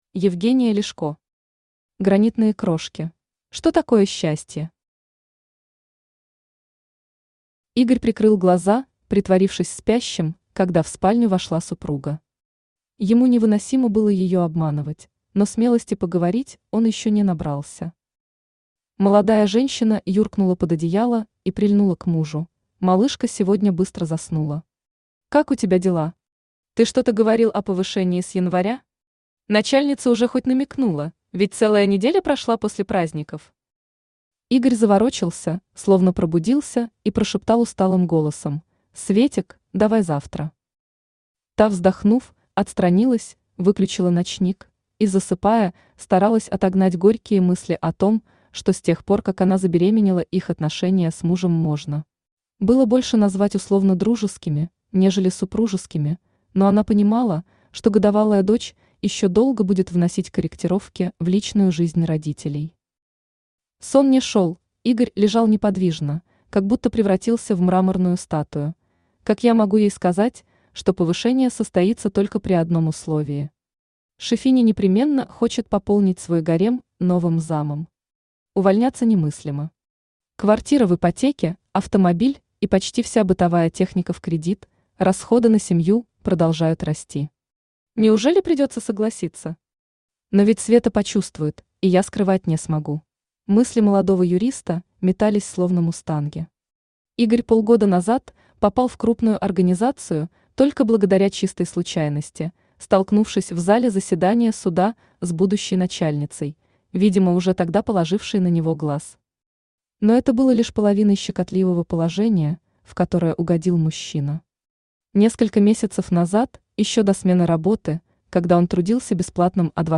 Aудиокнига Гранитные крошки Автор Евгения Ляшко Читает аудиокнигу Авточтец ЛитРес.